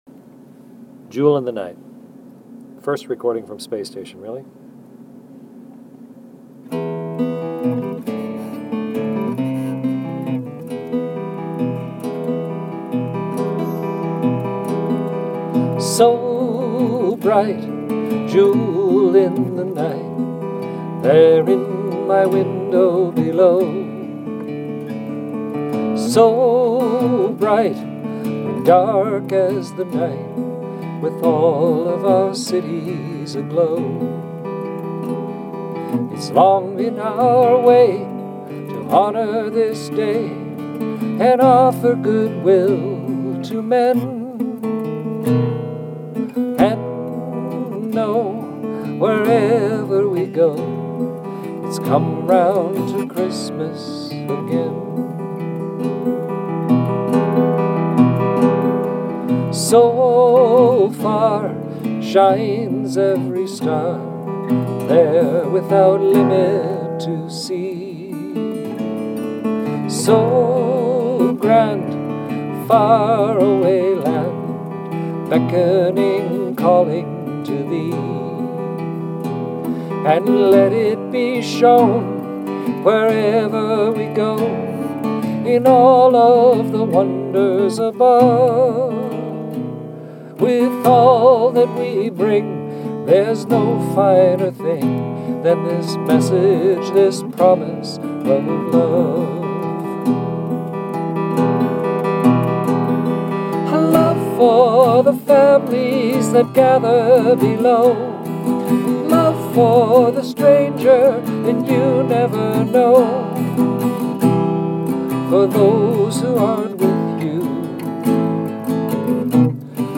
For the season, a Christmas Carol to the Earth. Jewel in the Night is an original song written by Canadian astronaut Chris Hadfield and was recorded on the ISS on Dec. 23, 2012.